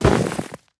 Minecraft Version Minecraft Version 25w18a Latest Release | Latest Snapshot 25w18a / assets / minecraft / sounds / entity / snowman / death1.ogg Compare With Compare With Latest Release | Latest Snapshot
death1.ogg